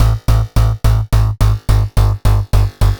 Index of /musicradar/future-rave-samples/160bpm